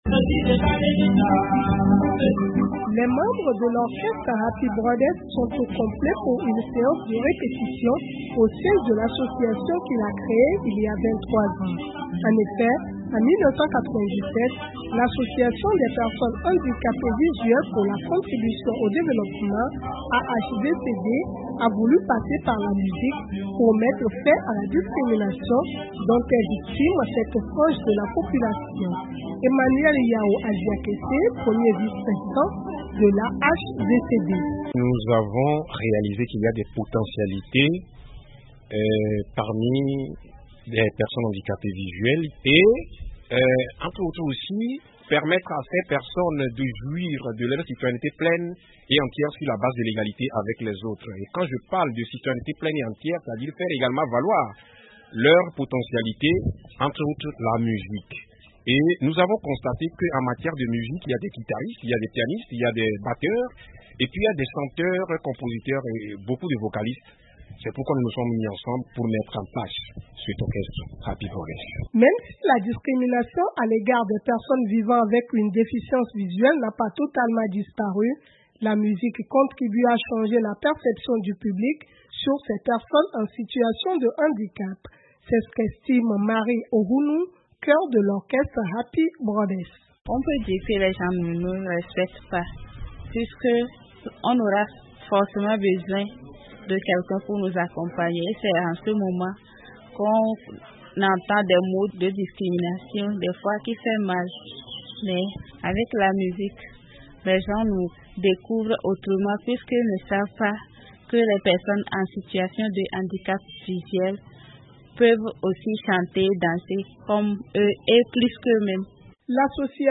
Les Happy Brothers, un orchestre togolais composé d'handicapés visuels